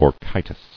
[or·chi·tis]